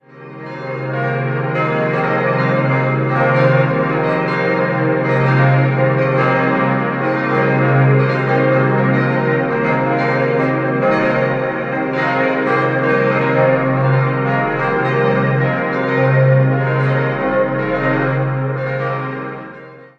Nun hatten beide Konfessionen ein eigenes Gotteshaus, denn zuvor diente seit 1657 die heutige evangelische Pfarrkirche als Simultaneum. 6-stimmiges Geläute: c'-es'-f'-g'-b'-c'' Die Glocken wurden im Jahr 1949 von der Gießerei Junker in der so genannten "Briloner Sonderbronze" in Brilon gegossen.